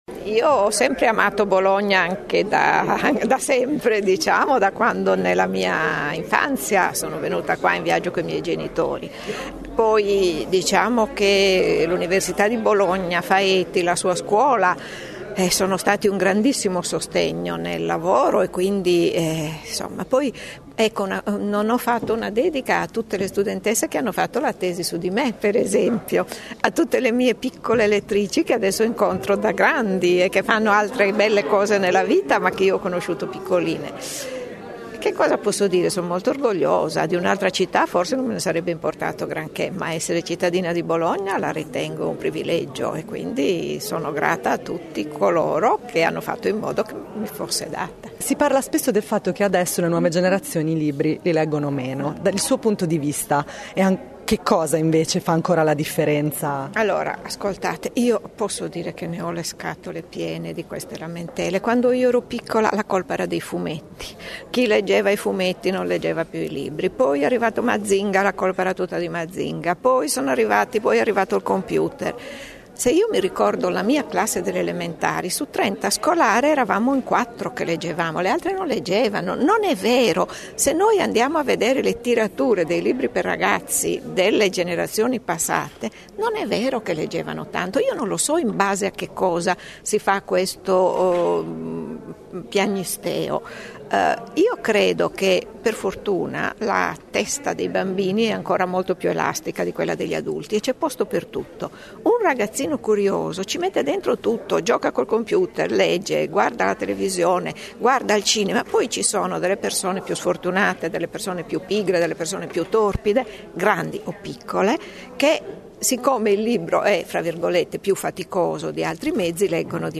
L’autrice di “Speciale Violante” e “Clorofilla dal cielo blu” è stanca di chi dice che i ragazzi “leggono meno”, apprezza le nuove tecnologie e ha fiducia nell’energia delle bambine, nonostante ci sia un “Tornatràs” nell’educazione di genere. L’abbiamo intervistata.